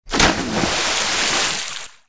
MG_cannon_splash.ogg